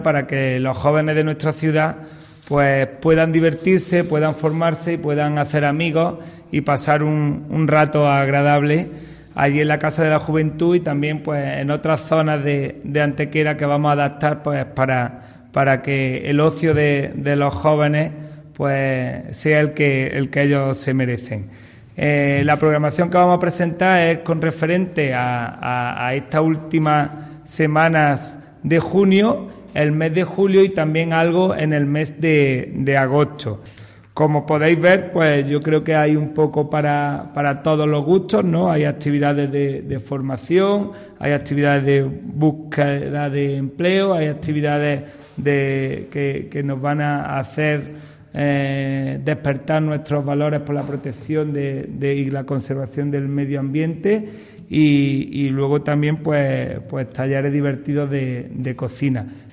El teniente de alcalde Juan Rosas presenta una variada y amplia programación del Área de Juventud para el verano
Cortes de voz